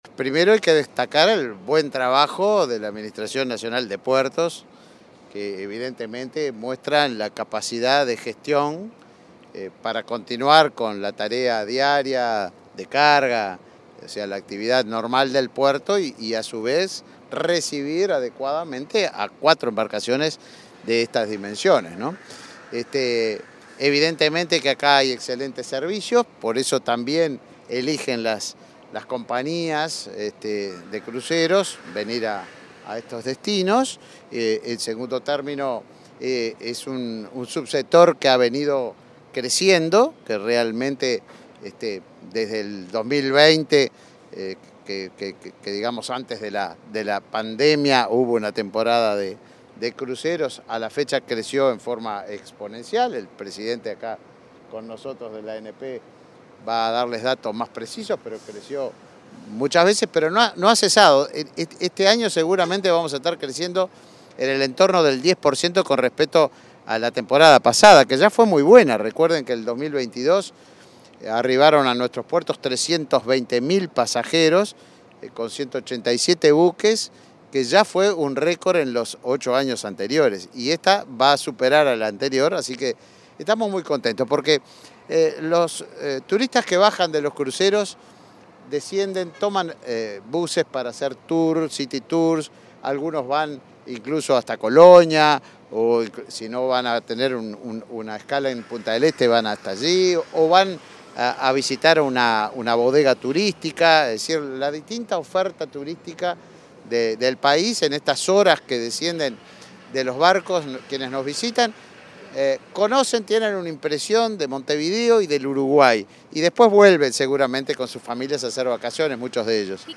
Declaraciones del ministro de Turismo y el presidente de la ANP